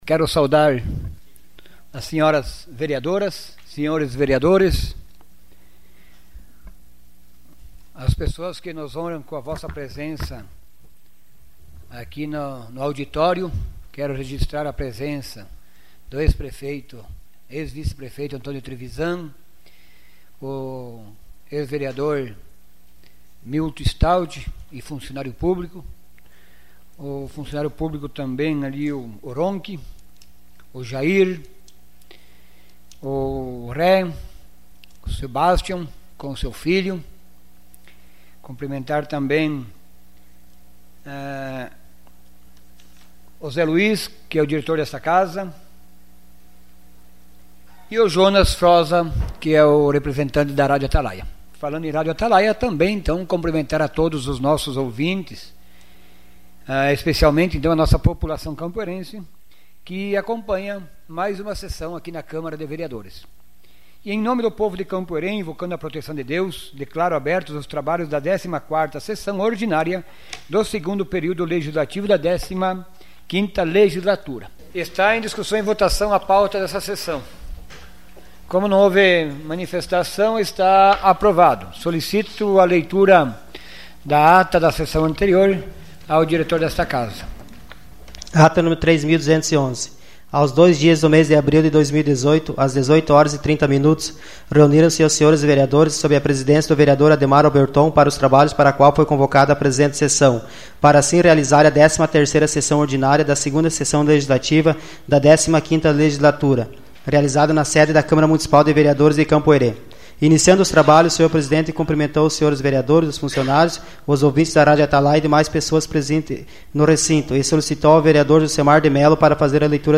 Sessão Ordinária dia 05 de abril de 2018.